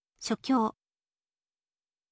shokyou